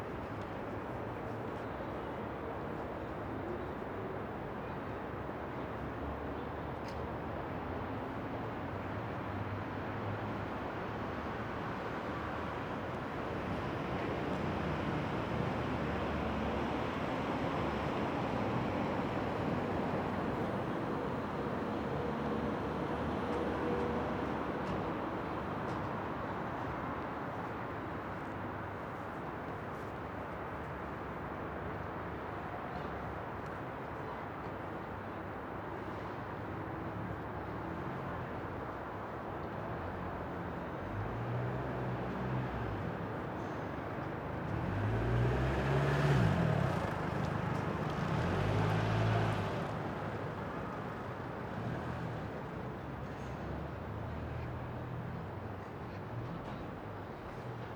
houseBackgroundSound.wav